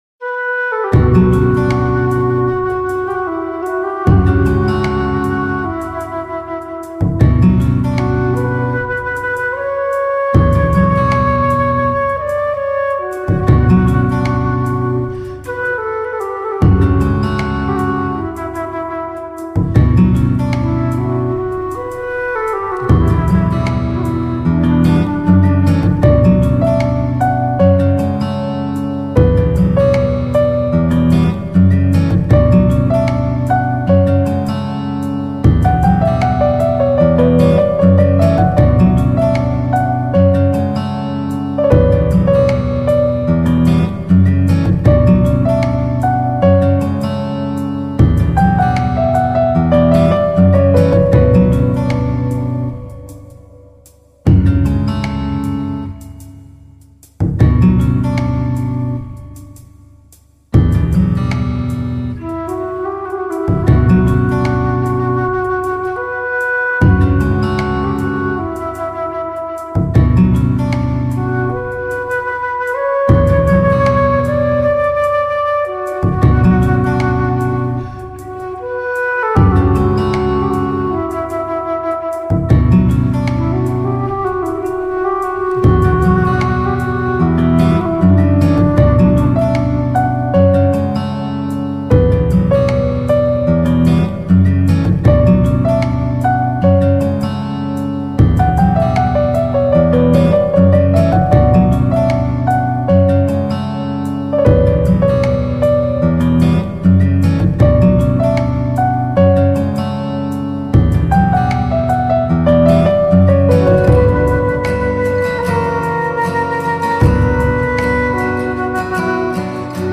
涉及分类：新世纪音乐 New Age
使用乐器：键盘 Keyboards